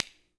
Clap25.wav